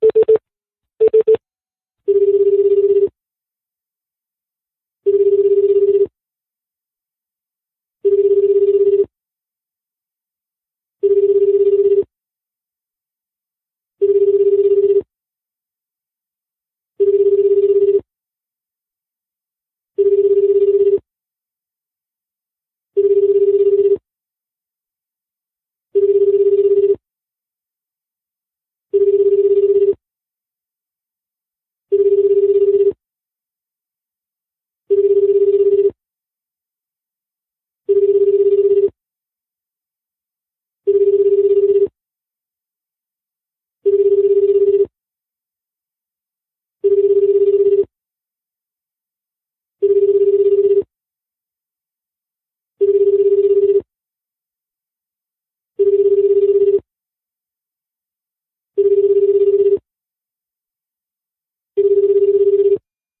일본의 기본 통화 연결음
일본400 ± 201 on, 2 off
일본의 표준 통화연결음은 1초 간격으로 울리고 2초간 멈추는 반복적인 소리이다. 이 소리는 400 ± 20 Hz의 주파수를 가지며, 진폭 변조는 15~20 Hz이다.[11] 사업용 전기 통신 설비 규칙(1985년 우정성령 제30호) 제33조에 규정되어 있다.